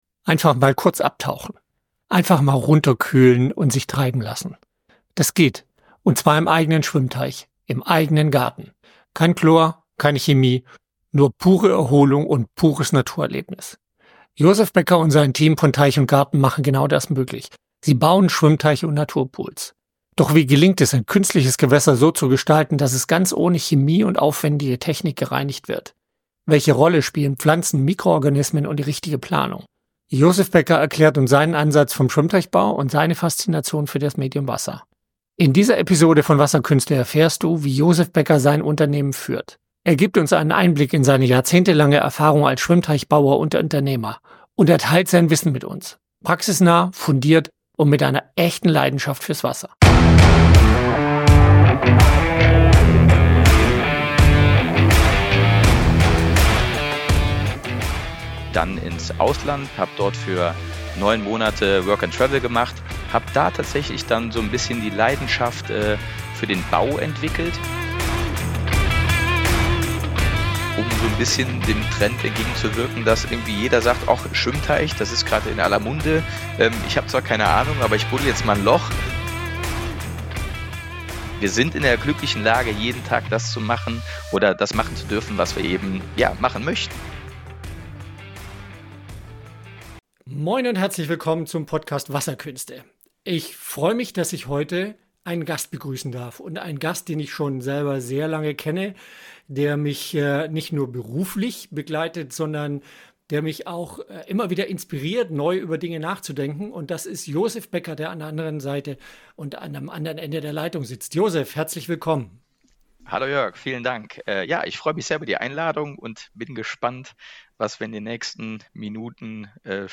Im Interview teilt er, was für ihn beim Bau von Schwimmteichen und Naturpools wichtig ist. Wir sprechen über die Herausforderungen und Besonderheiten der vollbiologischen Wasserreinigung, die Ästhetik naturnaher Wasserflächen und wie man aus dieser Leidenschaft ein nachhaltiges und erfolgreiches Geschäftsmodell entwickelt.